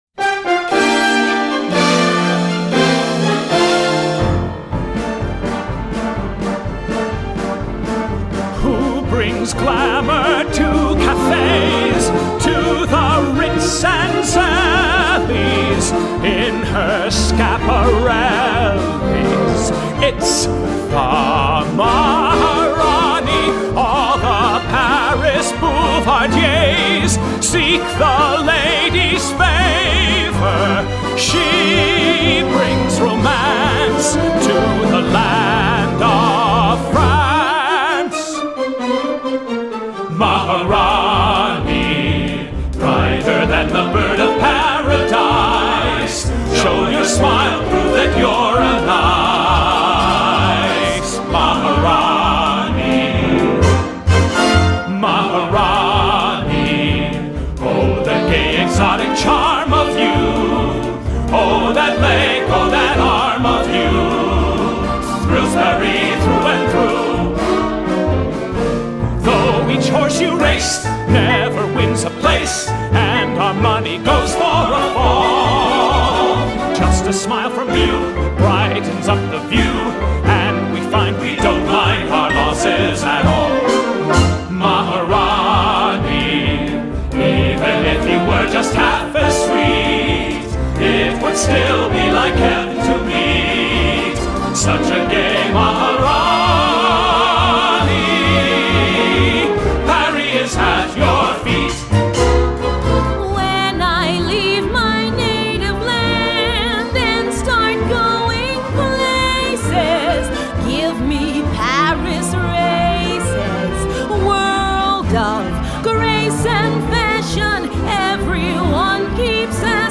1936   Genre: Musical   Artist